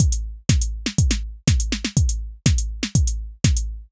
AIR Beat - Mix 4.wav